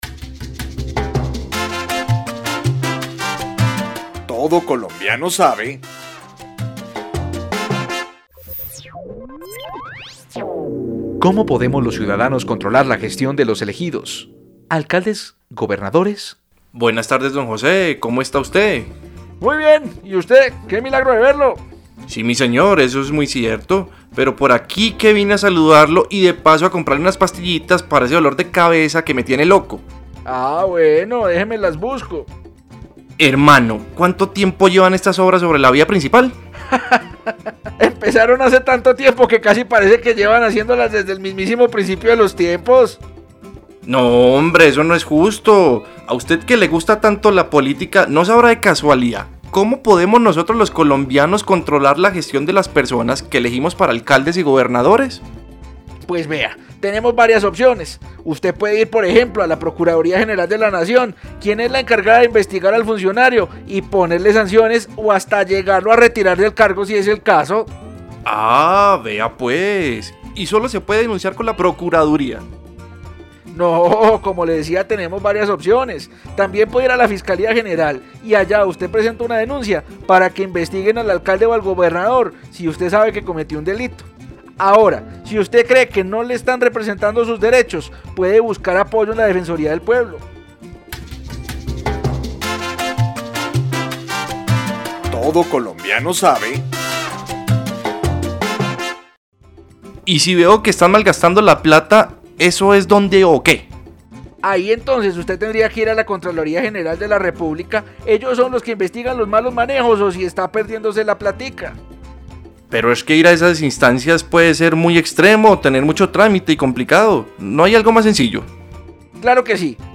Programas de radio , Participación ciudadana en Colombia , Elecciones y democracia en Colombia , Control político y rendición de cuentas , Irregularidades y delitos electorales , Colombia -- Grabaciones sonoras